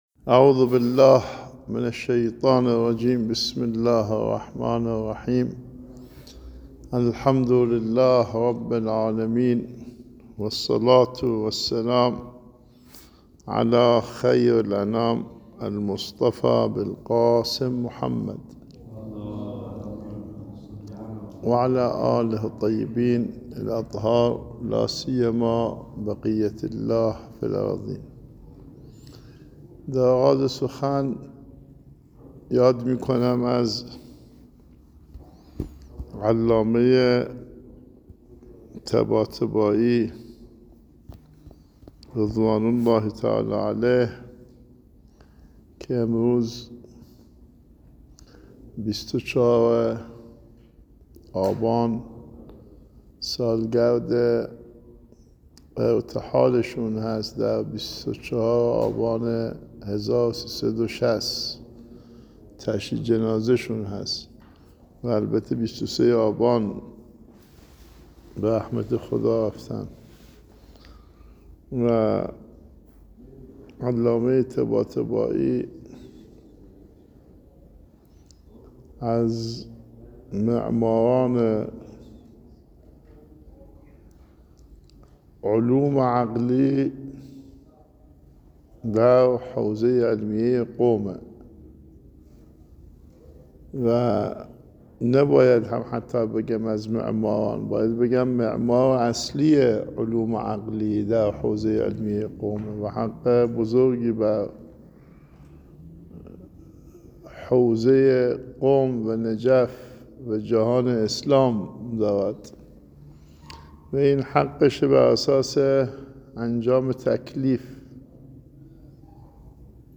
بیانات آیت الله کعبی به مناسبت سالگرد علامه طباطبایی